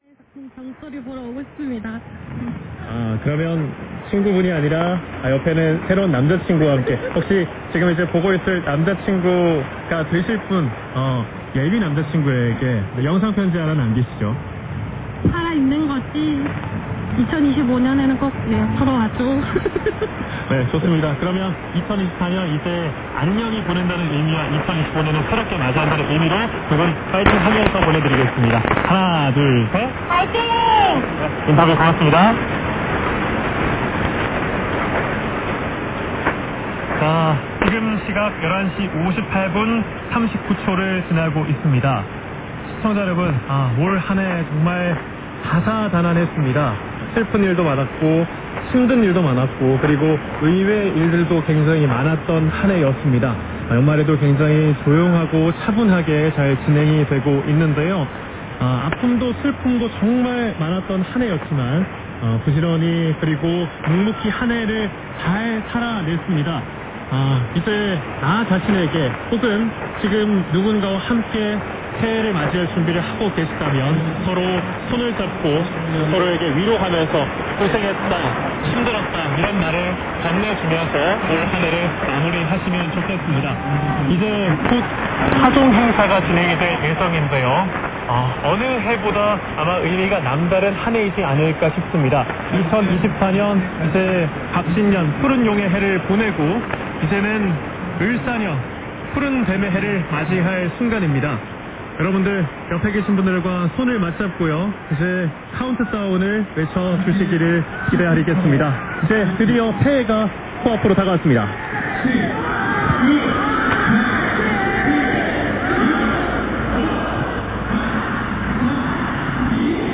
711kHz KBS第一放送で2025年新年の瞬間を聞きました。
野外からの中継でカウントダウン後に鐘が鳴ります。